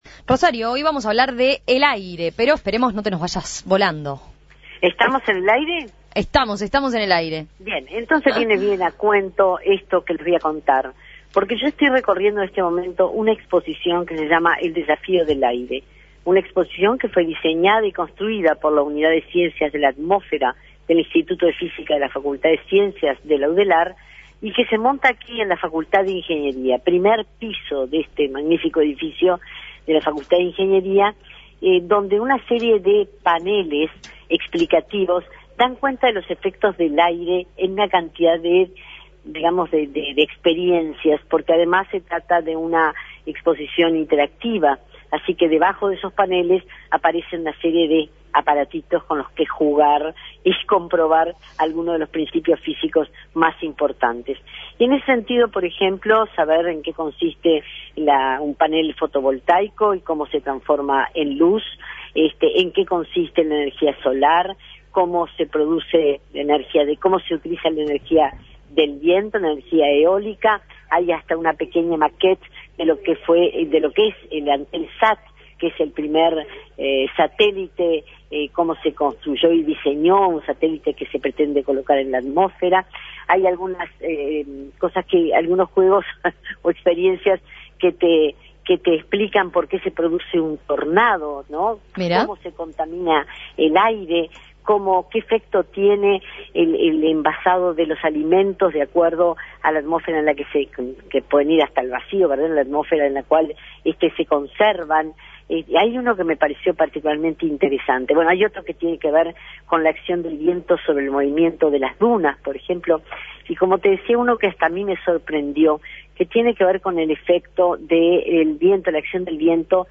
nos habla en su móvil sobre la exposición "El Desafío del Aire", ubicada en la Facultad de Ingeniería.